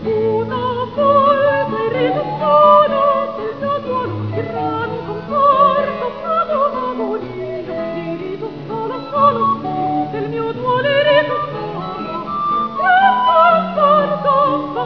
Aria
Italienische Kammerkantaten mit obligaten Instrumenten